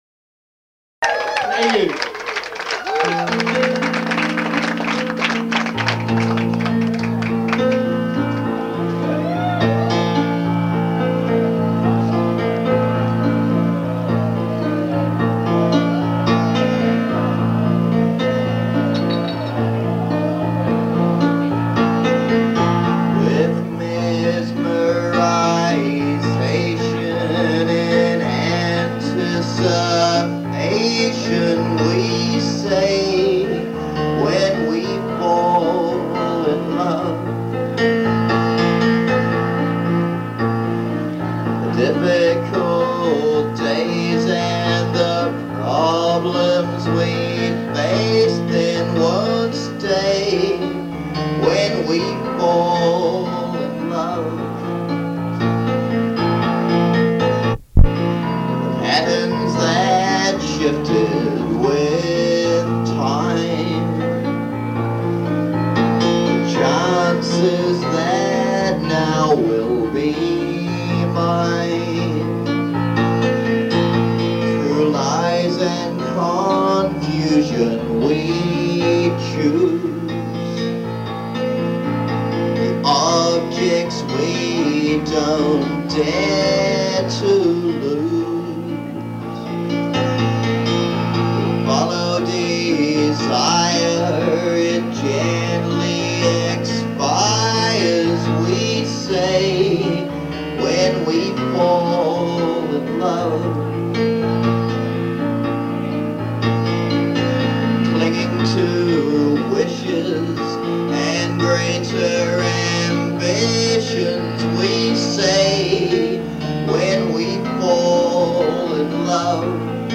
This was recorded in the audience at the Khyber on 8/19/93.